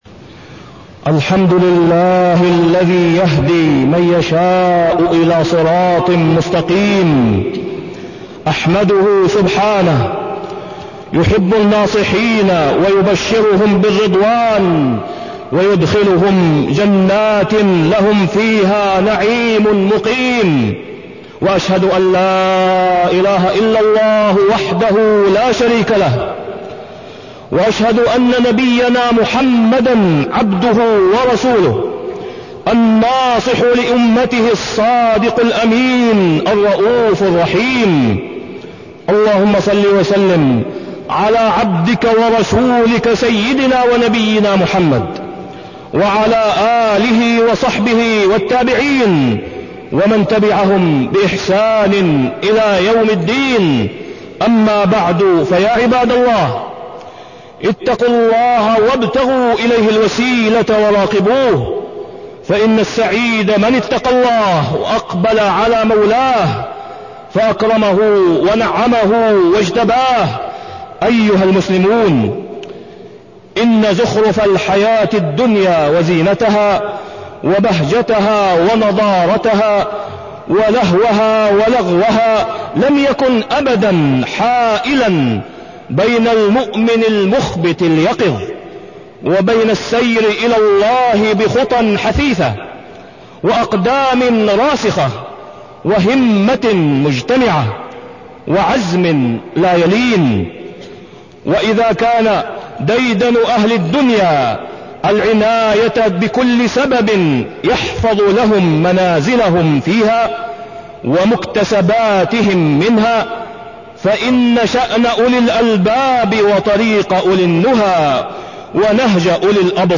تاريخ النشر ٩ شوال ١٤٢٣ هـ المكان: المسجد الحرام الشيخ: فضيلة الشيخ د. أسامة بن عبدالله خياط فضيلة الشيخ د. أسامة بن عبدالله خياط النصيحة